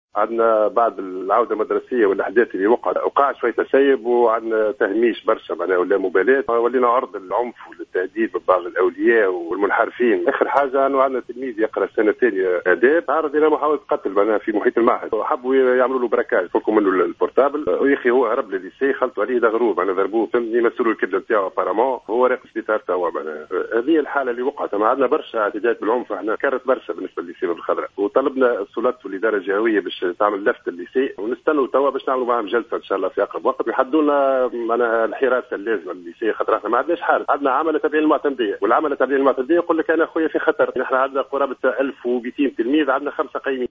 تصريح للجوهرة "اف ام"